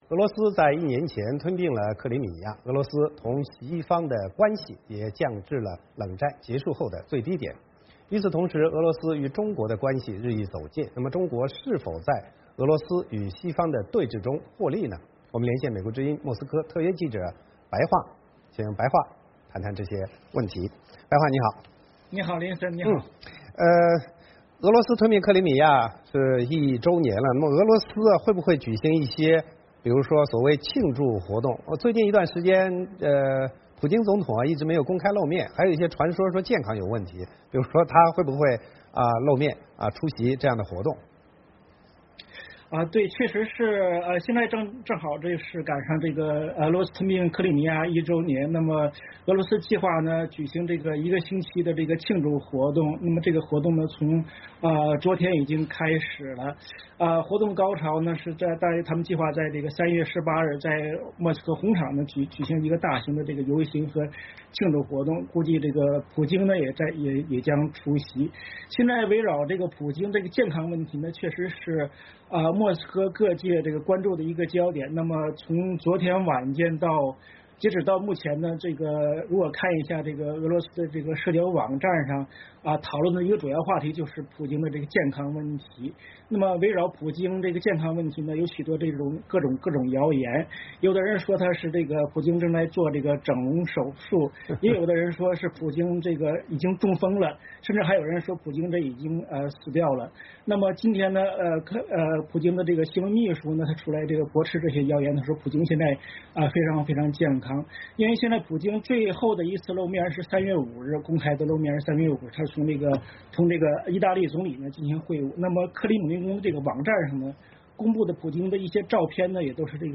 VOA连线：俄罗斯吞并克里米亚一周年